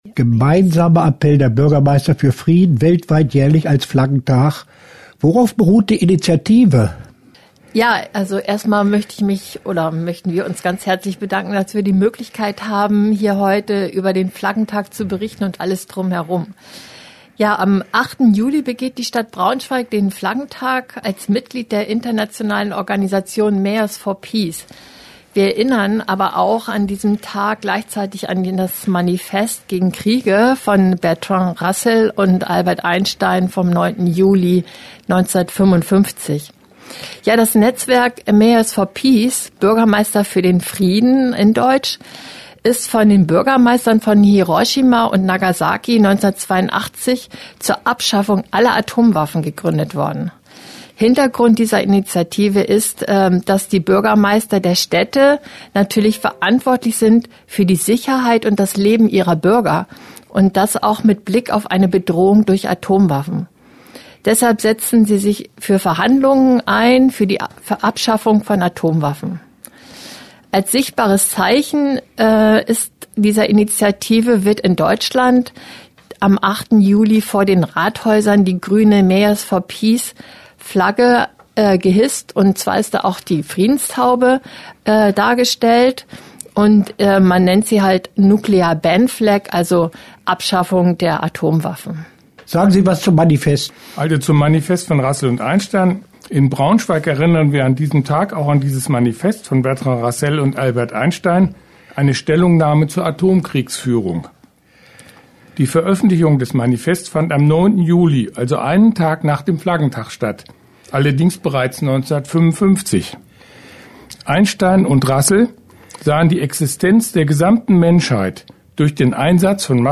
» Beitrag auf Radio Okerwelle zum Flaggentag